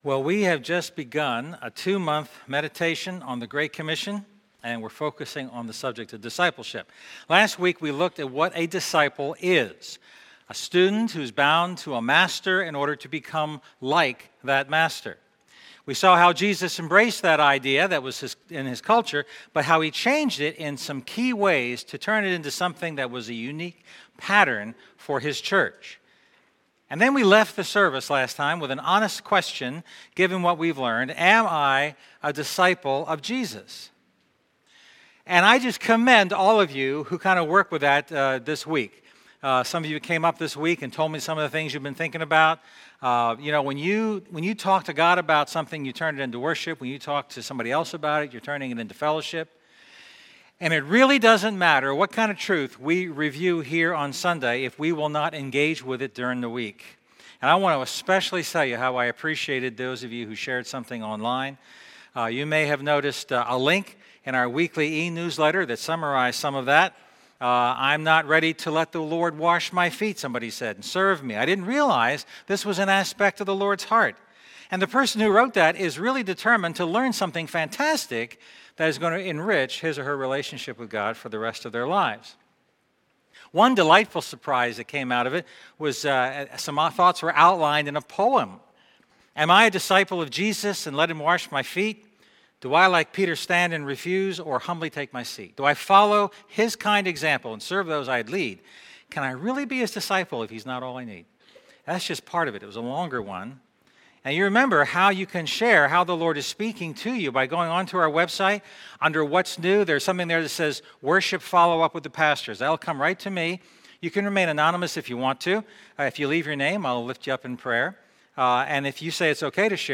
A message from the series "Discipleship."